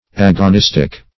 Agonistic \Ag`o*nis"tic\, Agonistical \Ag`o*nis"tic*al\, a. [Gr.